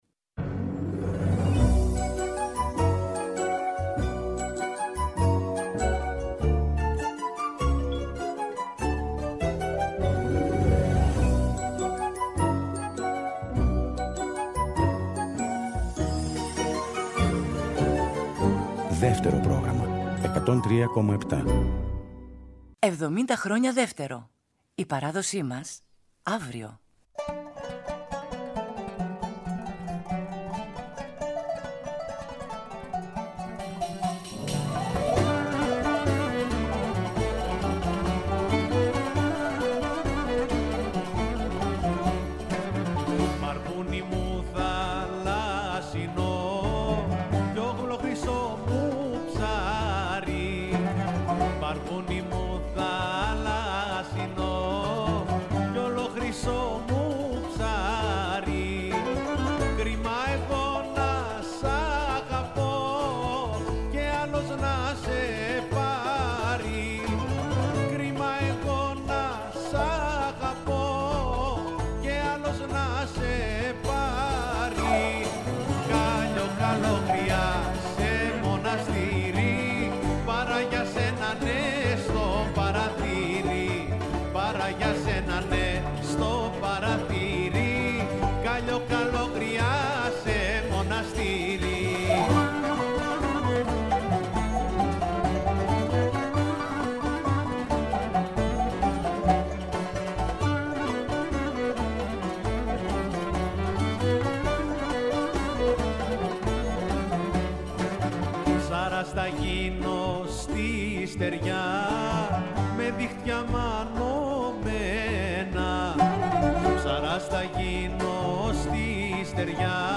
Ένας κύκλος 15 εκπομπών, που θα μεταδίδονται από τις 2 Μαΐου και κάθε μέρα έως τις 15 Μαΐου, στις 13.00, με ζωντανές ηχογραφήσεις σε Αθήνα και Θεσσαλονίκη με συγκροτήματα και μουσικές ομάδες από όλη την Ελλάδα, αλλά μουσικές συμπράξεις μόνο για τα γενέθλια του Δευτέρου Προγράμματος.
παραδοσιακές συνθέσεις και τραγούδια
Στόχος αυτής της μοναδικής σύμπραξης, είναι να αποτυπωθούν αυτοί οι σκοποί, με ένα σύγχρονο χαρακτήρα ως προς την ενορχήστρωση και την εκτέλεση, διατηρώντας όμως το υφολογικό πλαίσιο του τότε αισθήματος.